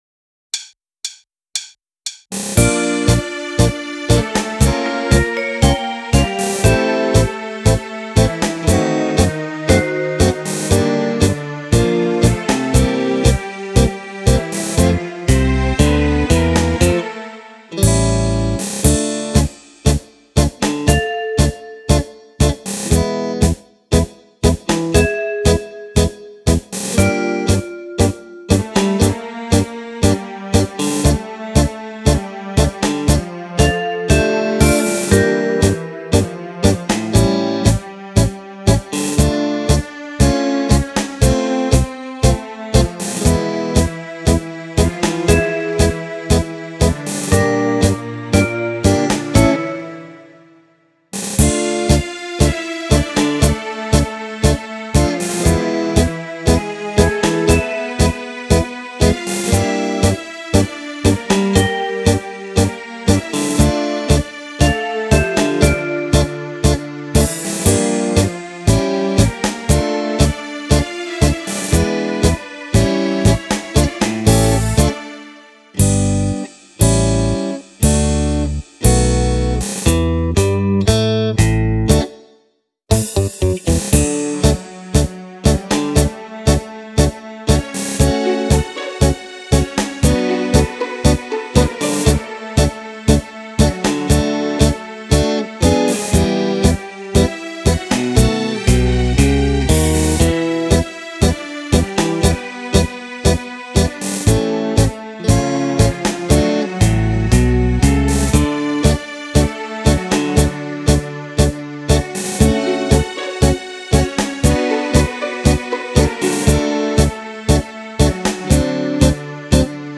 10 ballabili per Fisarmonica
Tango